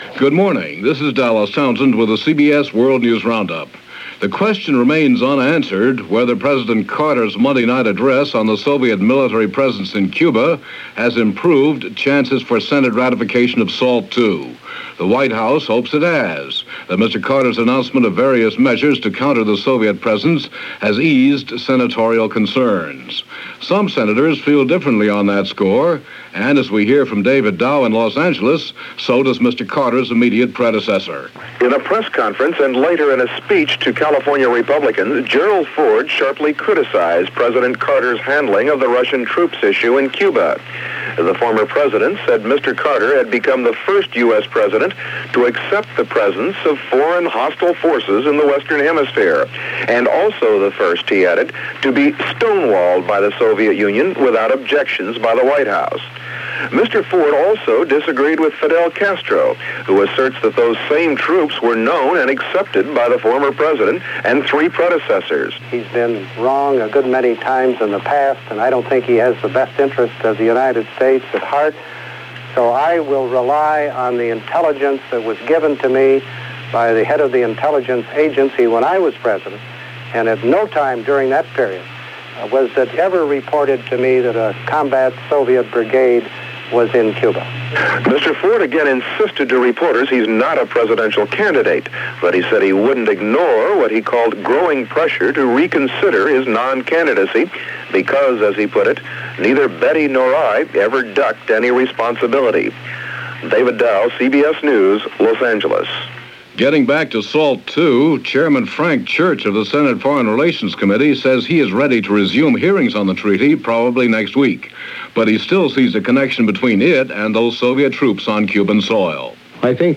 October 3, 1979 – CBS World News Roundup – Gordon Skene Sound Collection –